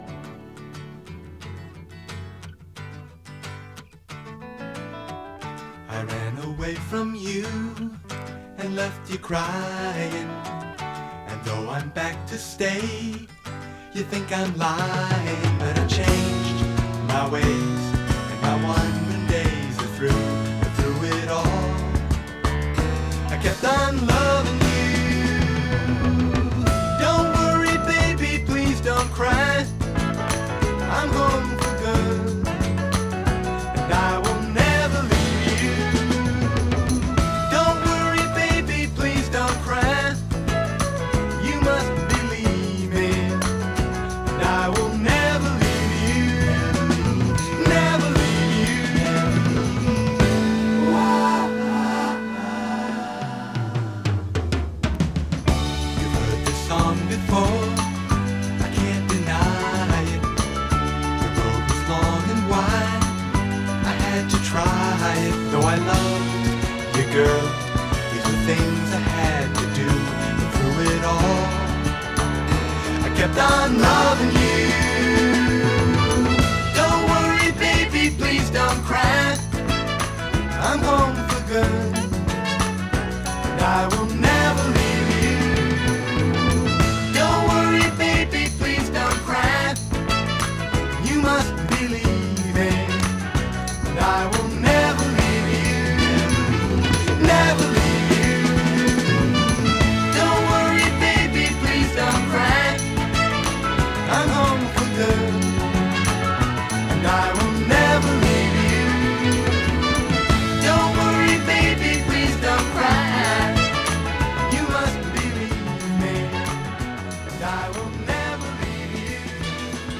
американский поп-дуэт
вокал и барабаны
фортепиано
Уверенный, похожий на колокольчик голос